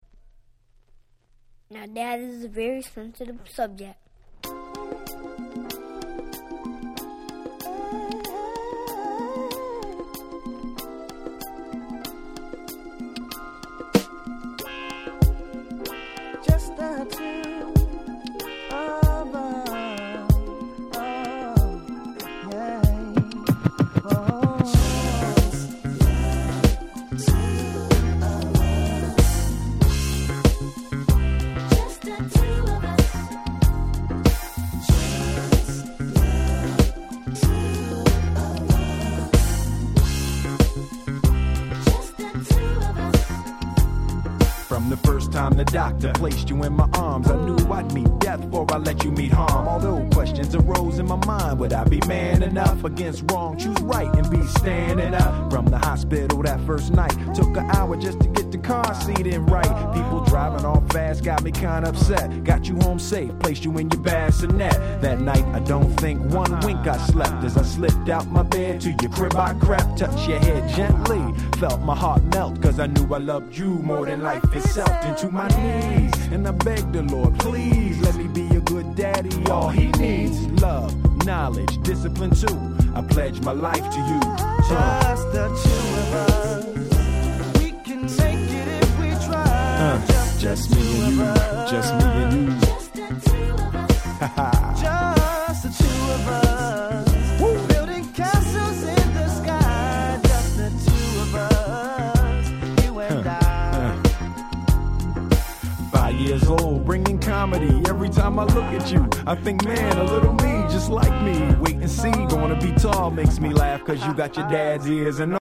97' Super Hit Hip Hop LP !!